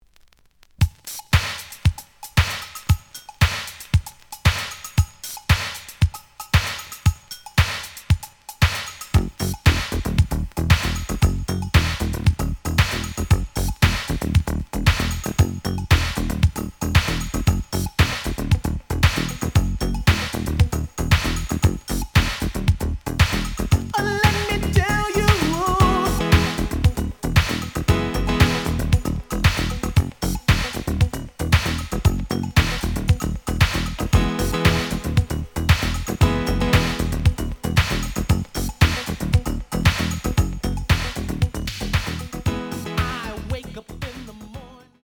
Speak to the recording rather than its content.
The audio sample is recorded from the actual item. Slight edge warp.